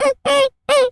pgs/Assets/Audio/Animal_Impersonations/mouse_emote_01.wav at master
mouse_emote_01.wav